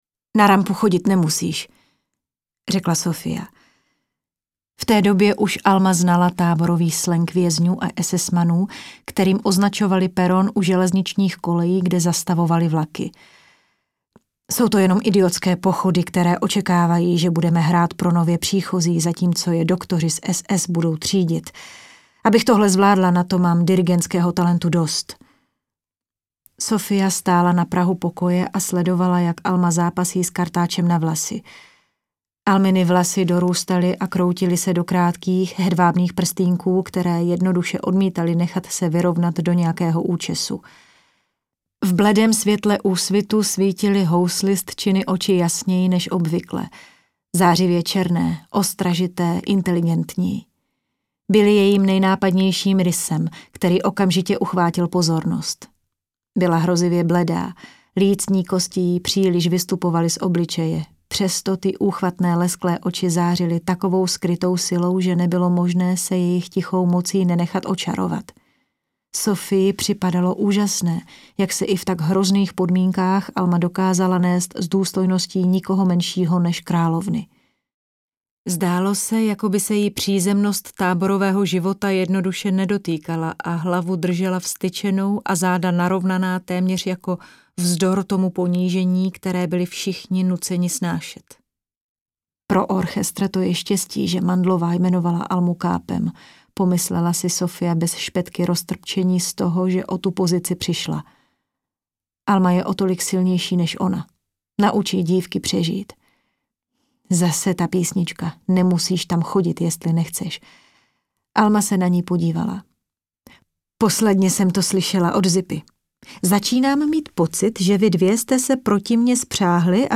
Houslistka z Osvětimi audiokniha
Ukázka z knihy